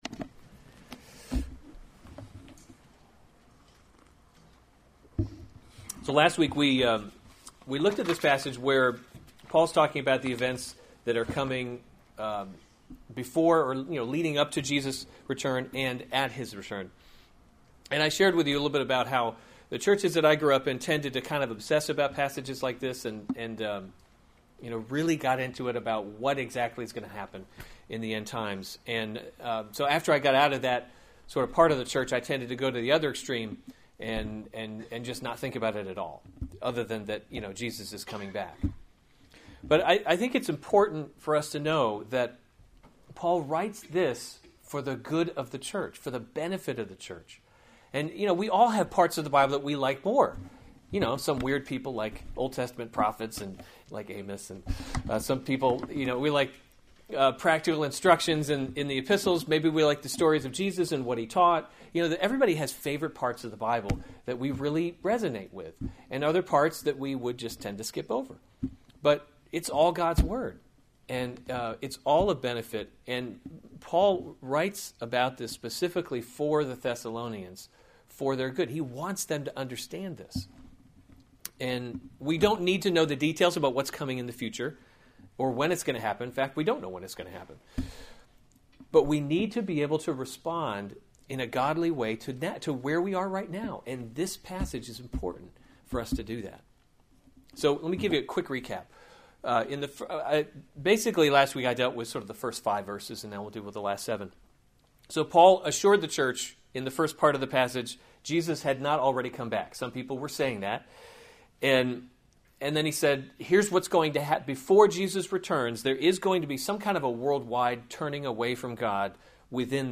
May 28, 2016 2 Thessalonians – The Christian Hope series Weekly Sunday Service Save/Download this sermon 2 Thessalonians 2:1-12 Other sermons from 2 Thessalonians The Man of Lawlessness 2:1 Now concerning […]